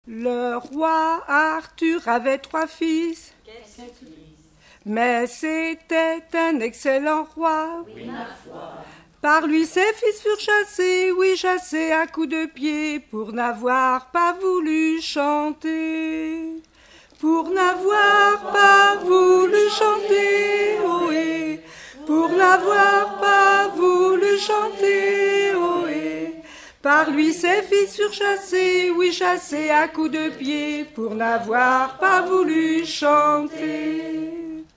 Genre strophique
Chansons traditionnelles et populaires